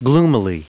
Prononciation du mot gloomily en anglais (fichier audio)
Prononciation du mot : gloomily